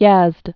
(yăzd)